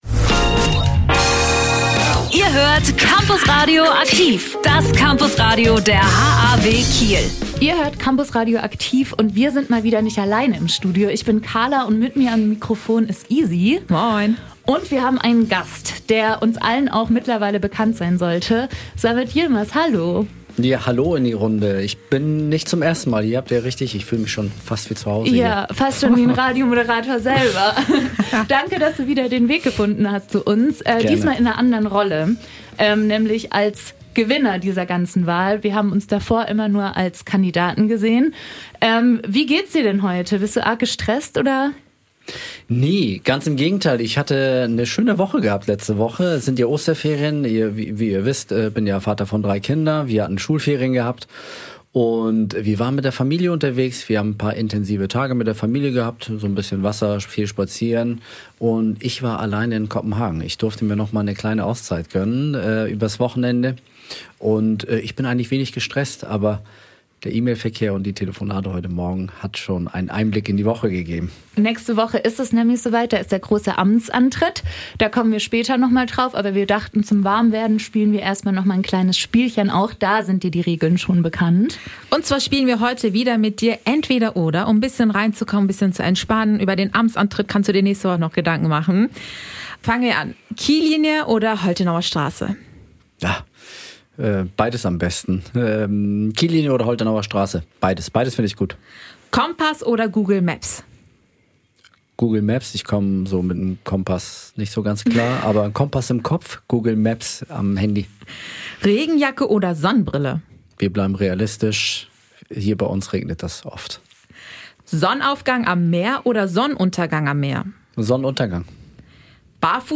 Im Interview spricht er darüber, wie eine Amtsübergabe eigentlich abläuft, welchen Führungsstil er als Bürgermeister anstrebt und warum er findet, dass Social Media für die Politik mittlerweile ein wichtiger Bestandteil geworden ist. Außerdem verrät er uns seinen Lieblingsplatz im Rathaus und warum Olympia für eine Chance für Kiel sein kann.